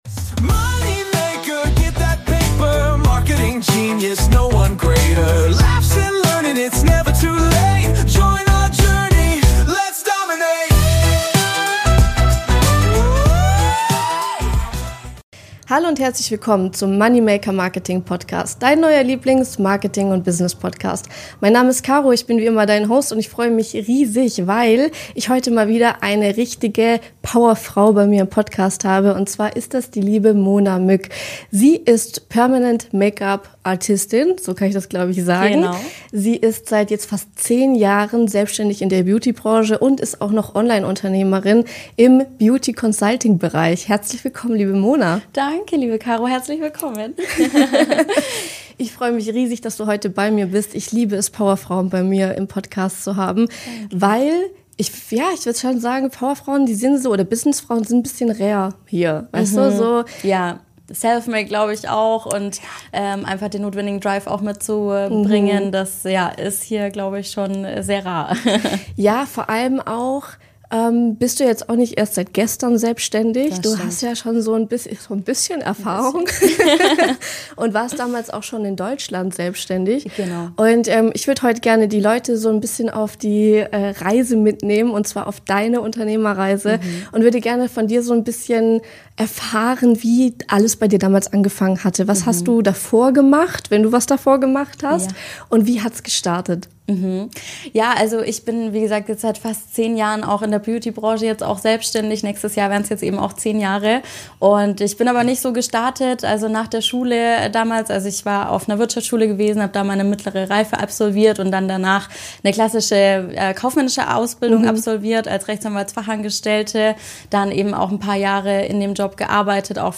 Ein Gespräch über Selbstvertrauen, Nachhaltigkeit im Business und warum der wahre Erfolg oft erst dann kommt, wenn man alles loslässt, was man nicht mehr ist.